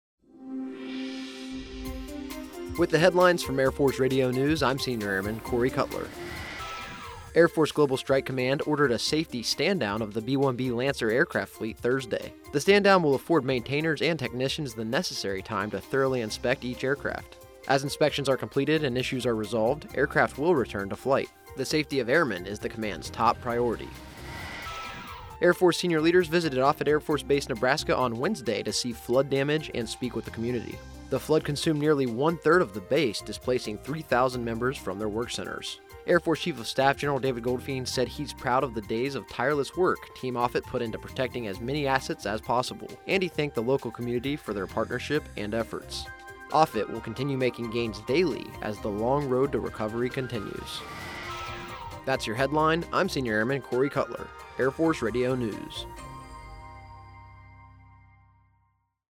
Today's stories: Air Force Global Strike Command ordered a safety stand-down of the B-1B Lancer aircraft fleet. Also, Air Force senior leaders visited Offutt Air Force Base, Nebraska, to assess flood damage.